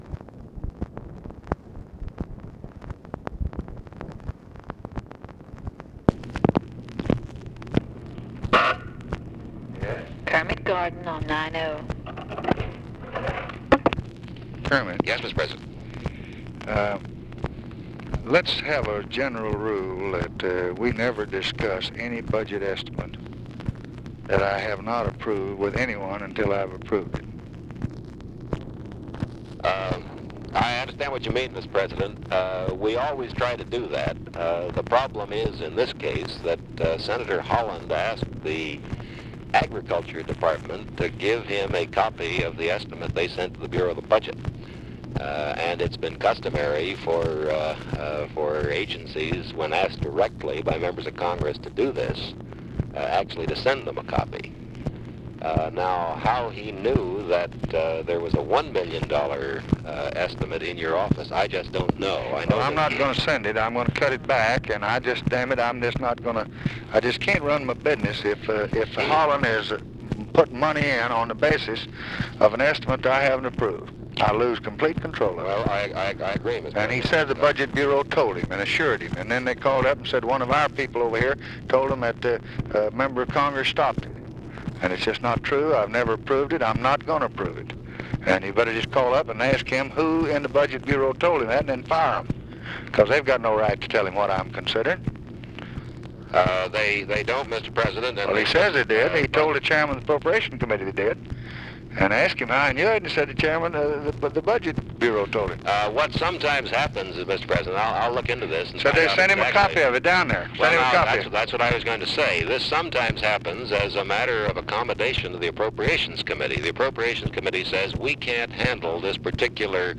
Conversation with KERMIT GORDON, August 4, 1964
Secret White House Tapes